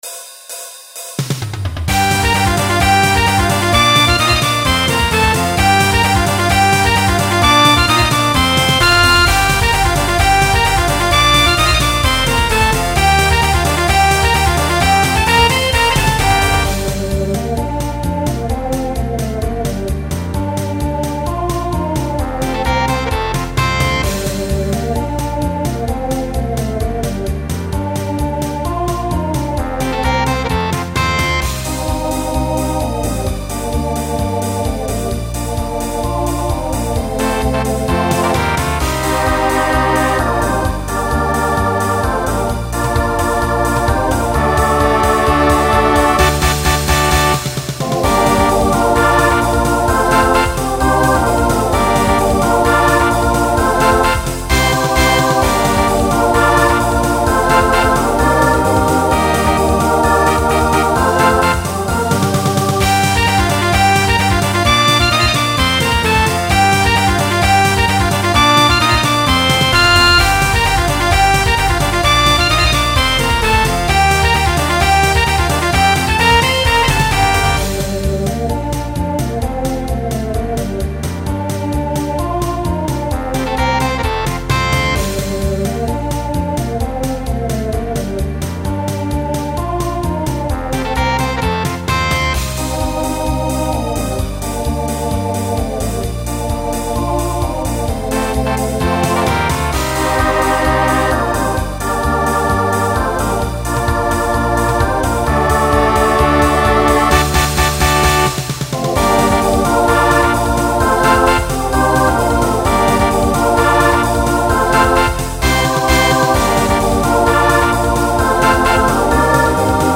Genre Folk , Rock
Instrumental combo
Transition Voicing SATB